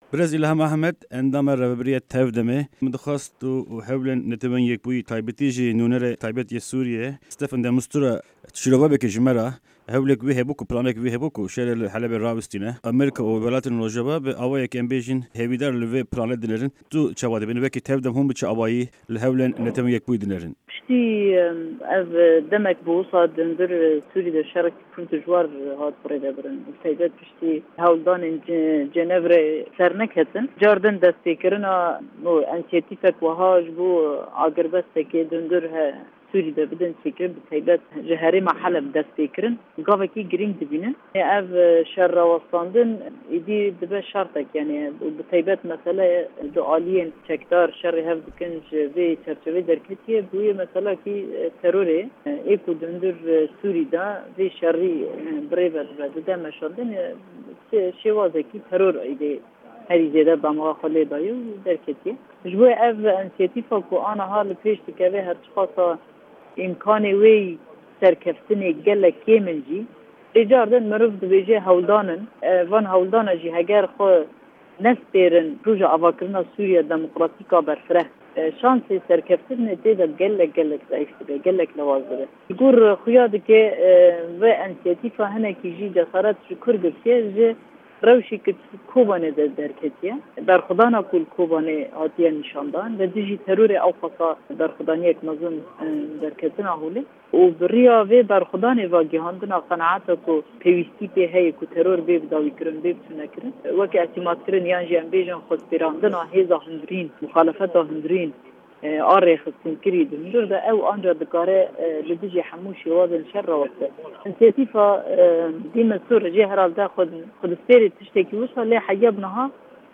Endama Rêvebirîya Tevgera Civaka Demokratîk (TEV-DEM) Îlham Ahmed, di hevpeyvîna Dengê Amerîka de hewlên de Mistura dinirxîne û ser konferansên Qahîre û Moskow nêrînên xwe dîyar dike.